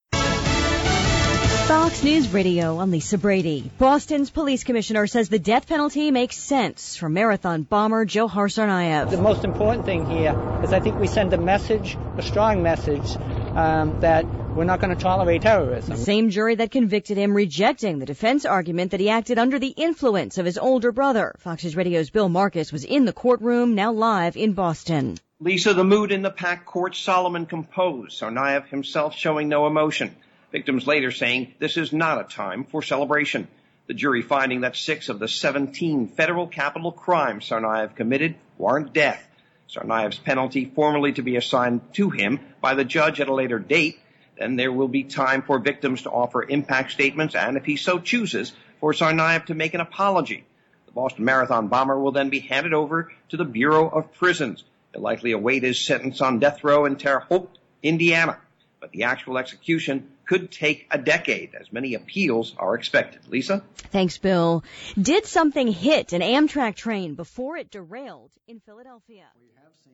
LIVE 7PM –